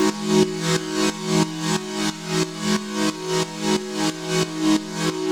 Index of /musicradar/sidechained-samples/90bpm
GnS_Pad-MiscA1:8_90-E.wav